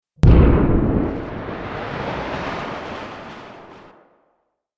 دانلود آهنگ دریا 7 از افکت صوتی طبیعت و محیط
دانلود صدای دریا 7 از ساعد نیوز با لینک مستقیم و کیفیت بالا
جلوه های صوتی